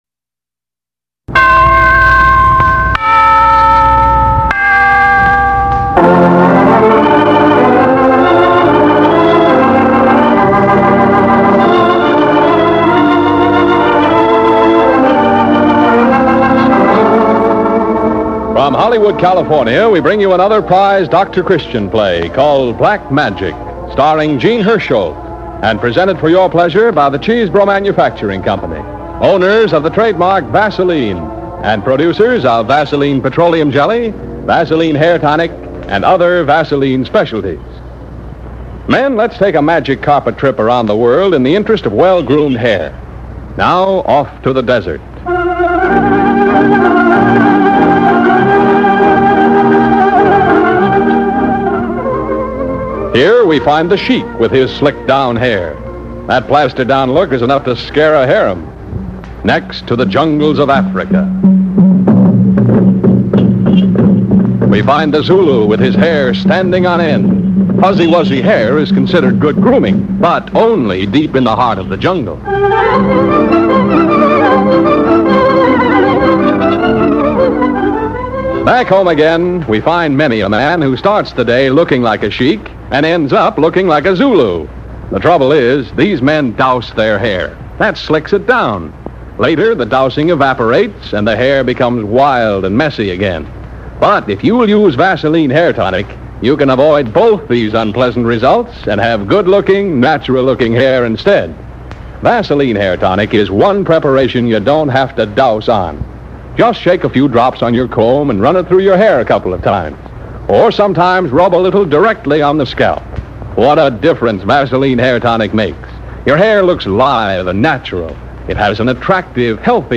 Doctor Christian, Starring Jean Hersholt